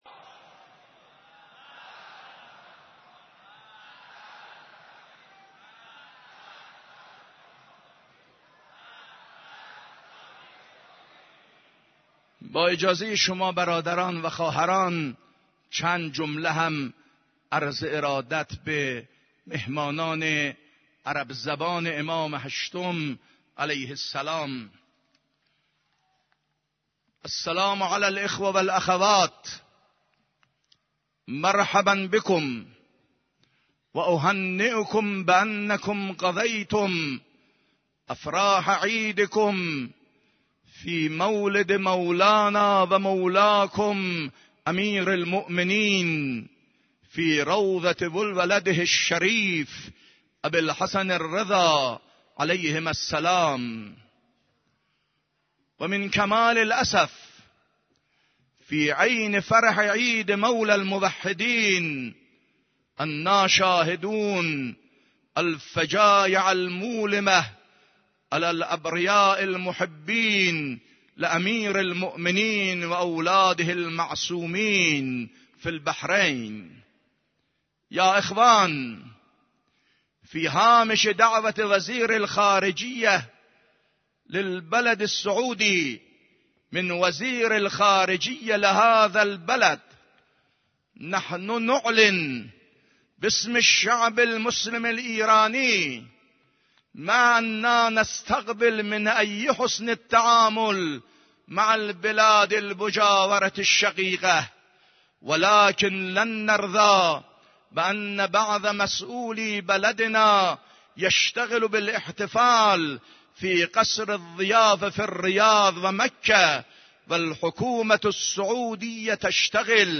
خطبه عربی نماز جمعه 26 اردیبهشت.mp3
خطبه-عربی-نماز-جمعه-26-اردیبهشت.mp3